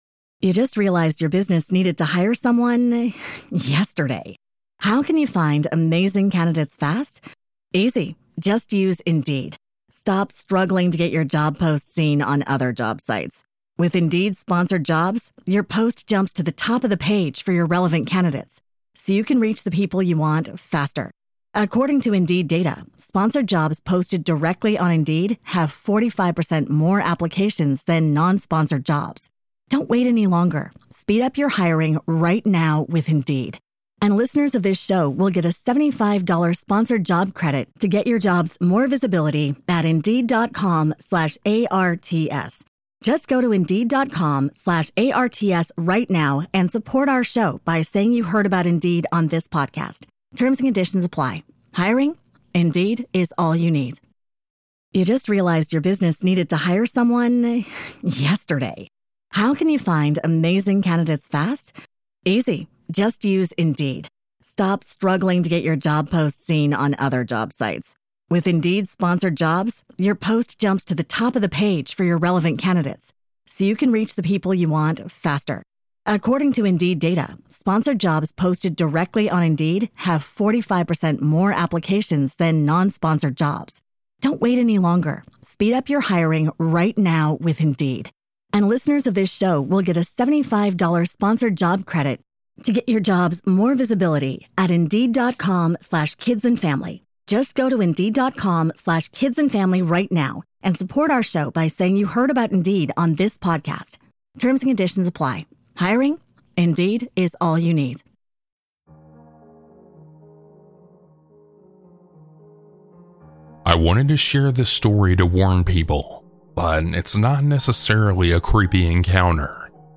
- A Huge Thanks to these talented folks for their creepy music!